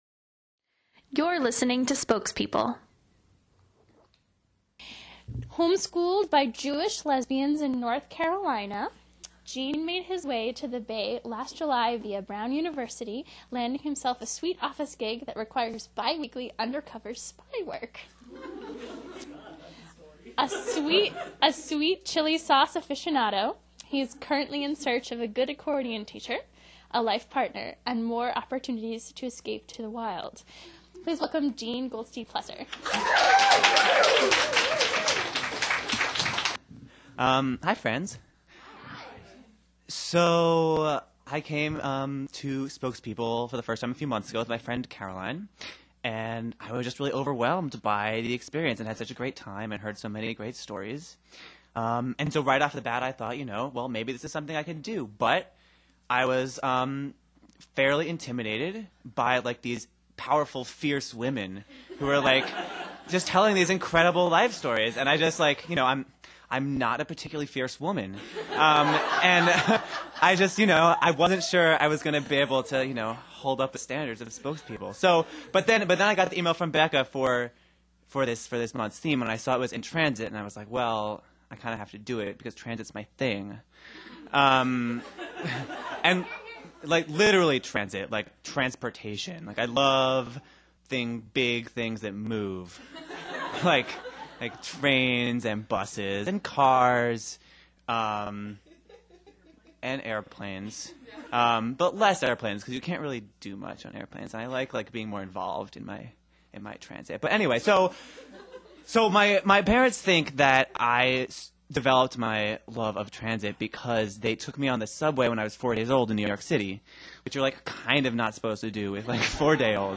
This story comes from our fourth storytelling event in April 2012: In Transit.